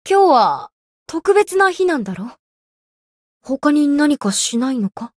灵魂潮汐-南宫凛-情人节（摸头语音）.ogg